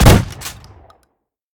pump-shot-6.ogg